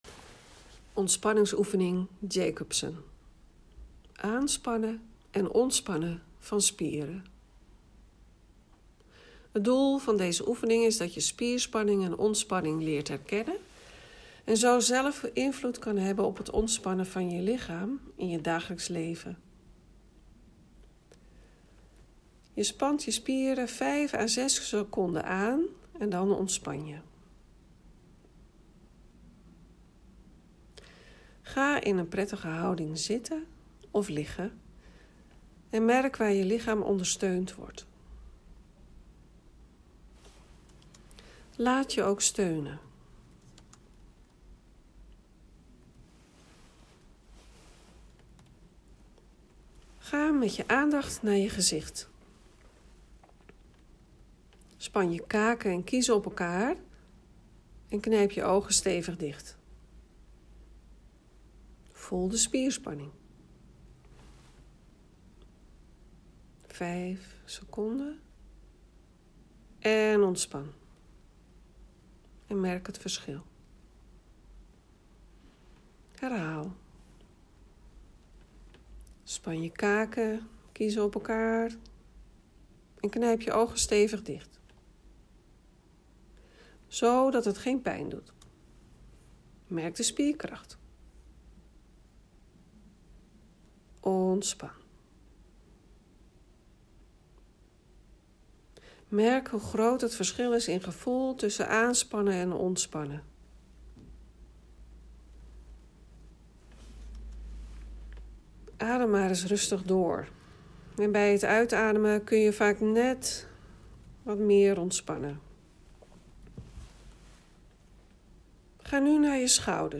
Ontspanningsoefening Jacobson (10 min) Met deze ontspanningsoefeningen leer je spanning in je spieren los te laten.